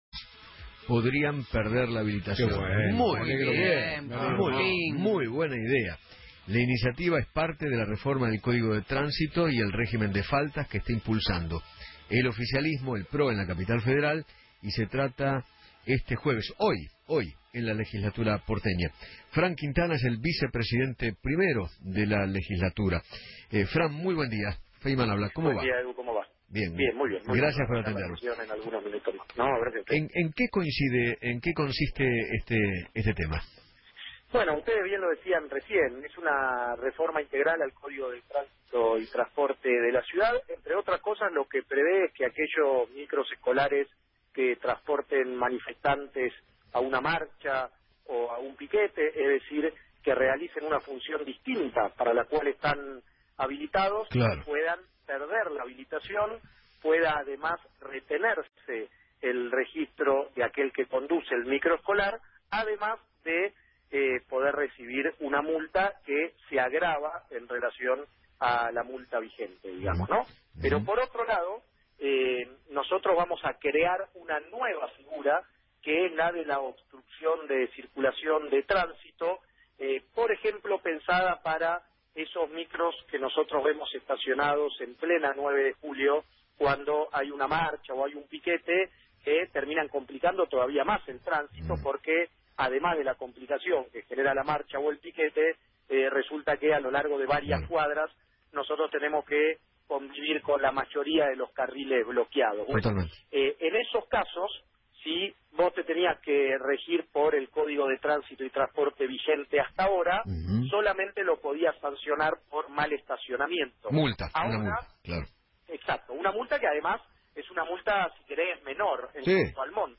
Francisco Quintana, Vicepresidente de la Legislatura porteña, habló en Feinmann 910 y contó que  “Es una reforma del código de transito y transporte de la Ciudad, prevé que los colectivos que trasladen manifestantes pueden perder la habilitación, o una multa. Por otro lado, vamos a crear una nueva figura que es la obstrucción de transito, pensada para los micros que están estacionados en la 9 de Julio, complicando el transito”